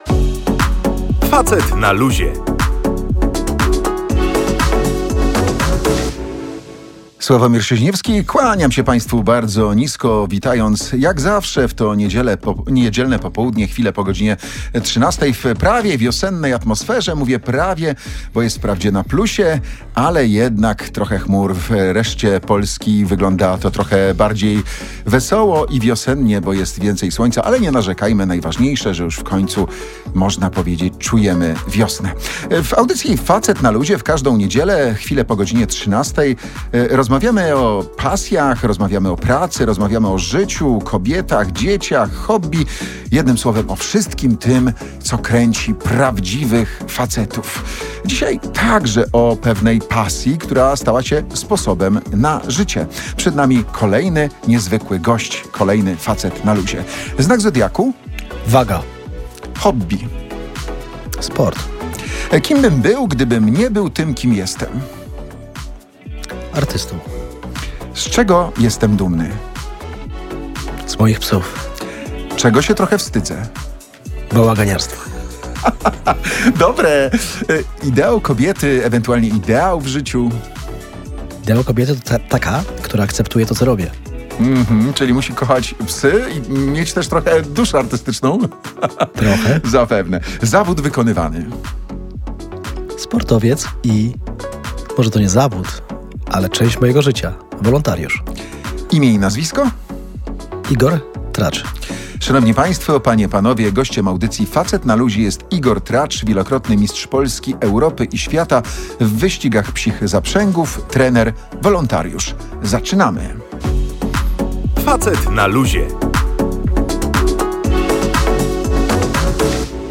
W niedzielnej rozmowie w Radiu Gdańsk opowiadał, jak wygląda tam życie, co mówią ludzie pozostali na Ukrainie oraz który z polityków pomógł jego ekipie przewieźć pierwsze zwierzęta przez granicę.